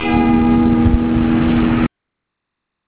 第五章　とりあえず３コード
そのコードとは、ＣとＧとＦです。